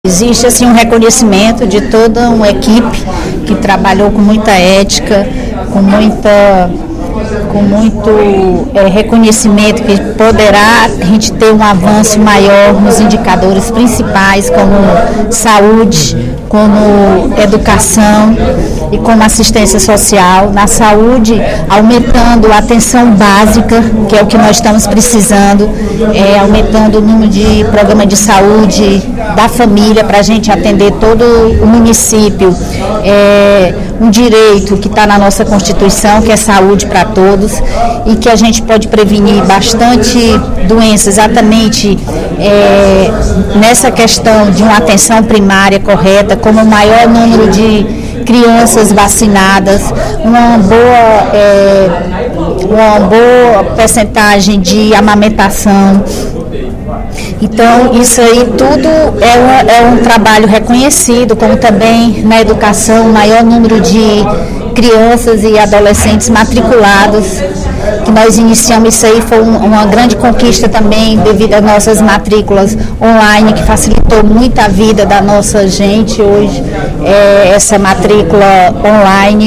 A deputada Bethrose (PRP) comentou, no primeiro expediente da sessão plenária desta quinta-feira (06/12) da Assembleia Legislativa, a concessão do Selo Unicef Município Aprovado 2009-2012 à cidade de São Gonçalo do Amarante, no Vale do Curu.